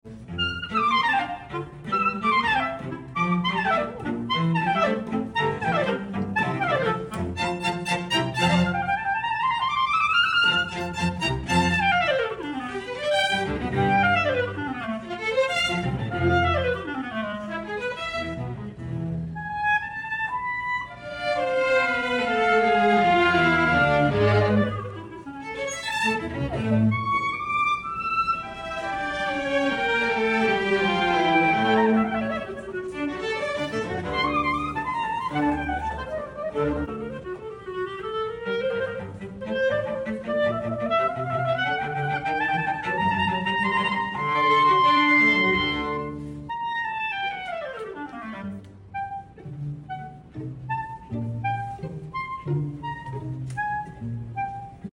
CLARINET*